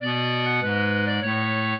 clarinet
minuet5-9.wav